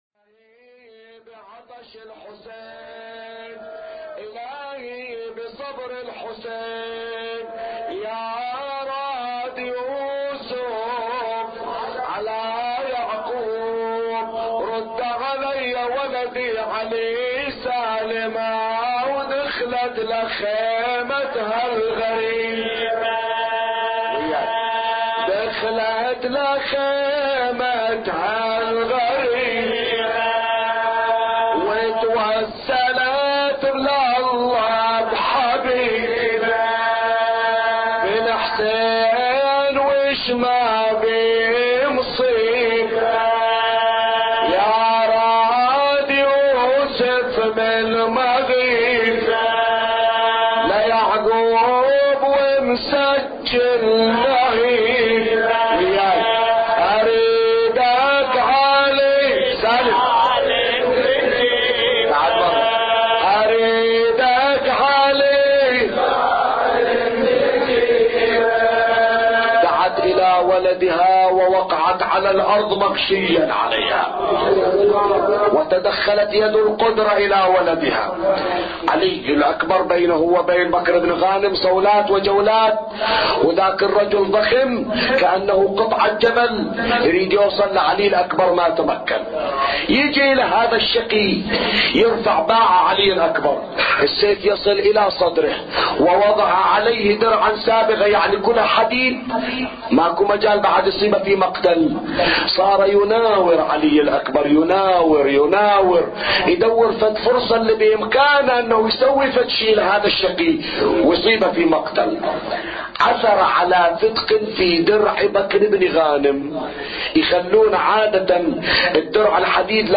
أبيات حسينية – ليلة التاسع من شهر محرم